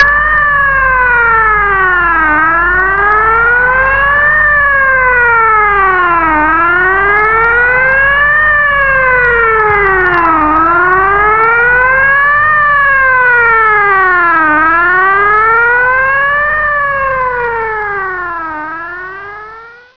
Signal national alerte
Signal Alerte - audio.wav